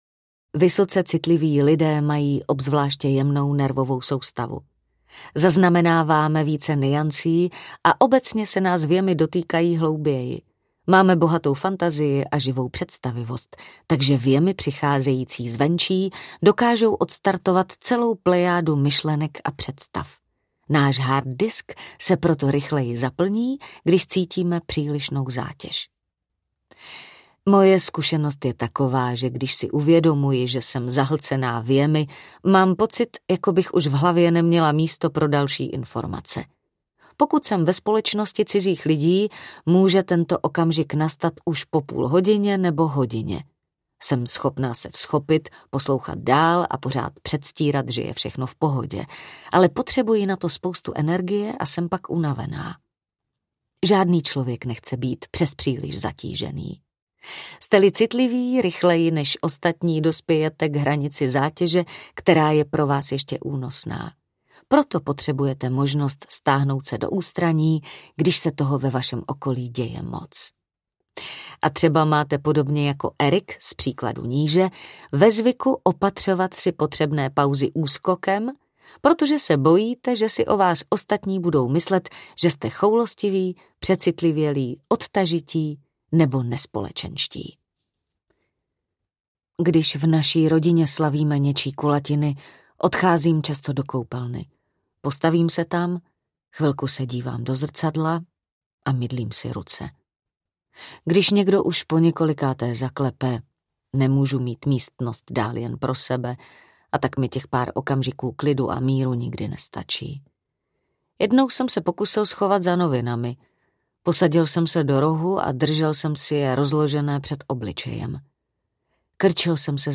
Přecitlivělost není slabost audiokniha
Ukázka z knihy
Vyrobilo studio Soundguru.